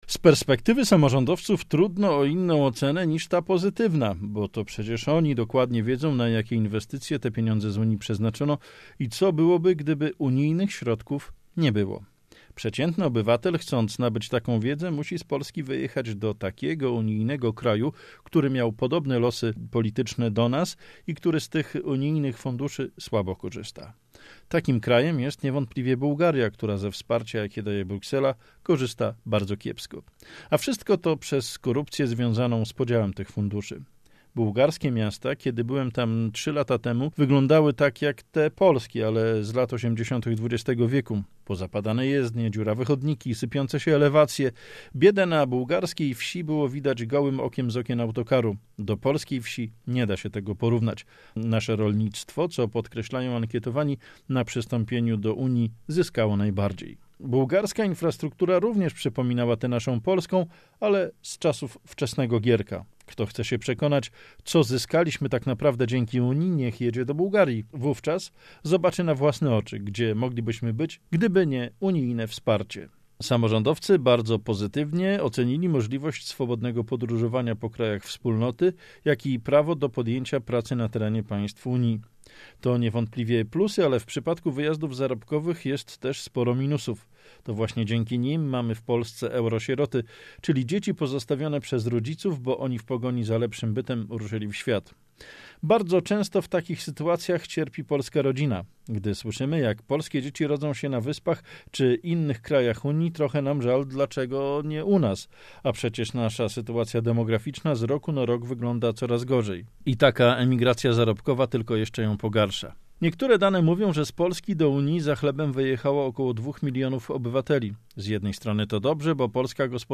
felieton